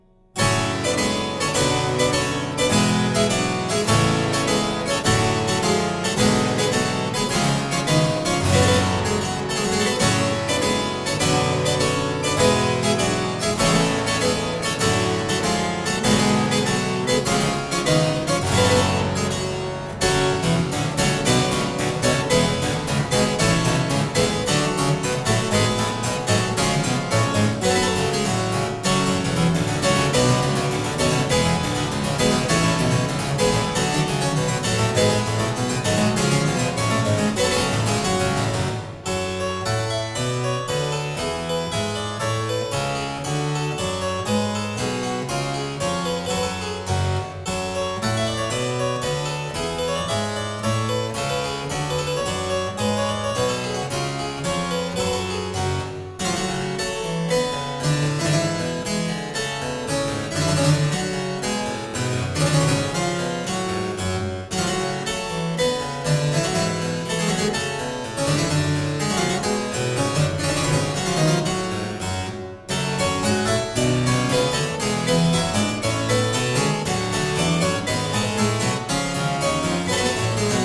我們依然被大鍵琴版本所震撼，聽見了想像之外的聲響。
在原配器的表現上具有無可取代的聽覺效果，